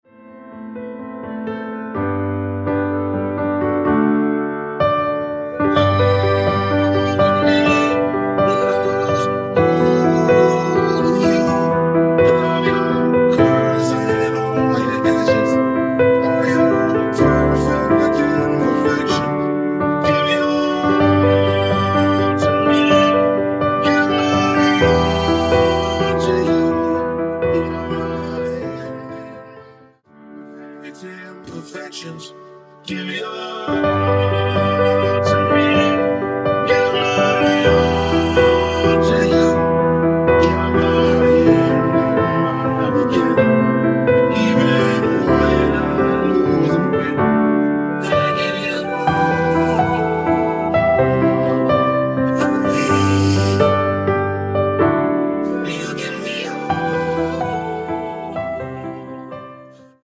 원키에서(-1)내린 멜로디와 코러스 포함된 MR입니다.
◈ 곡명 옆 (-1)은 반음 내림, (+1)은 반음 올림 입니다.
앞부분30초, 뒷부분30초씩 편집해서 올려 드리고 있습니다.